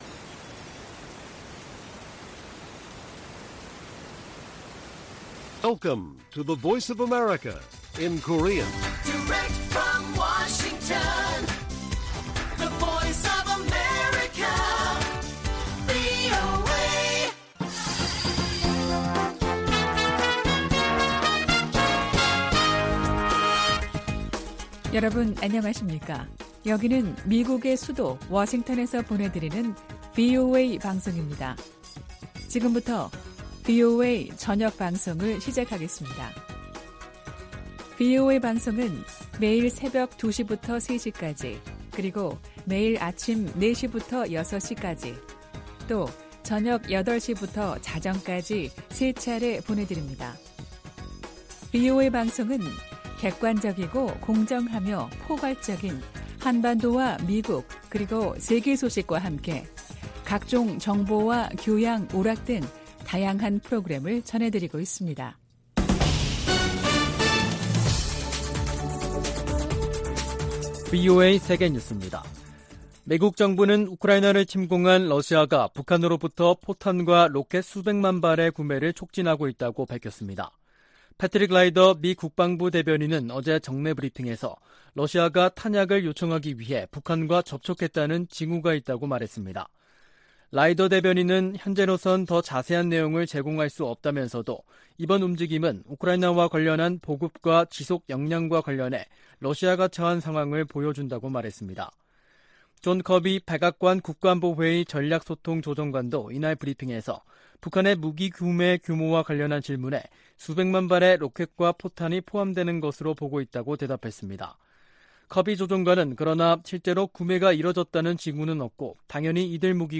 VOA 한국어 간판 뉴스 프로그램 '뉴스 투데이', 2022년 9월 7일 1부 방송입니다. 미국과 한국, 일본 북핵 수석대표들이 북한의 도발에 단호히 대응하겠다고 거듭 강조했습니다. 미국 정부가 러시아의 북한 로켓과 포탄 구매에 대해 유엔 안보리 결의 위반이라고 지적했습니다. 유엔이 강제실종과 관련해 북한에 총 362건의 통보문을 보냈지만 단 한 건도 응답하지 않았다며 유감을 나타냈습니다.